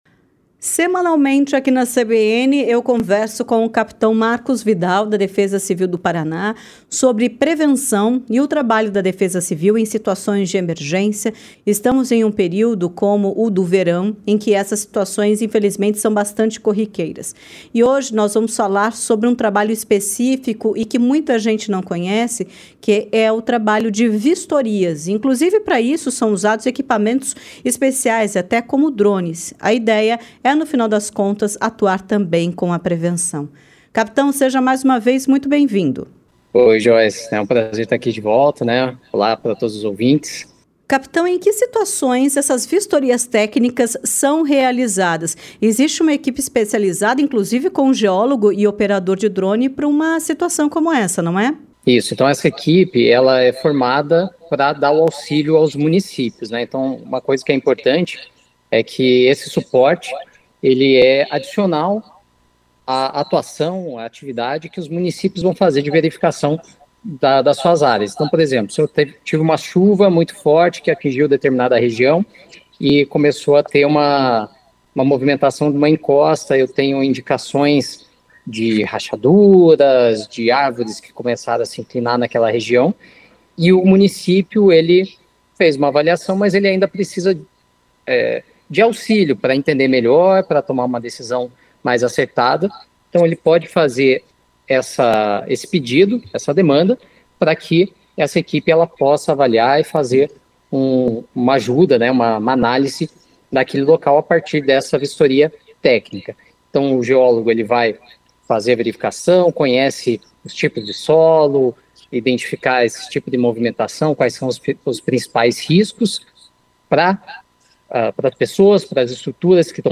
ENTREVISTA-DEFESA-CIVIL-PR-PARA-SEXTA-0703.mp3